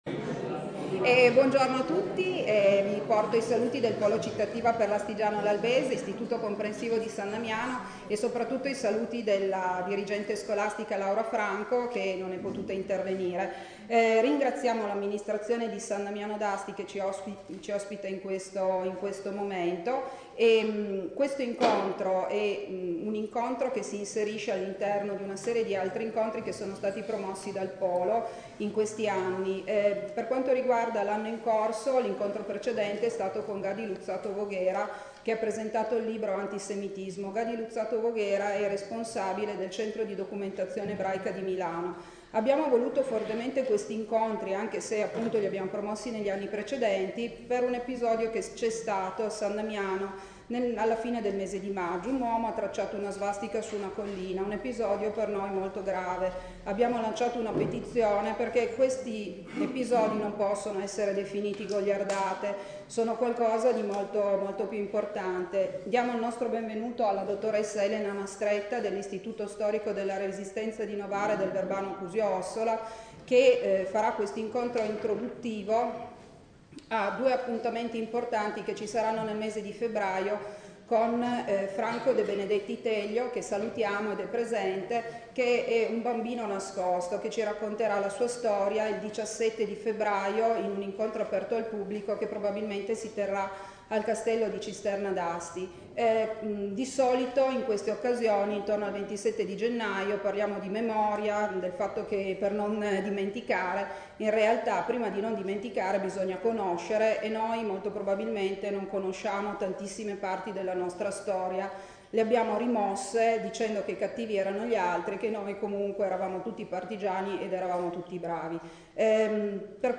REGISTRAZIONE DELL’INCONTRO IN FORMATO MP3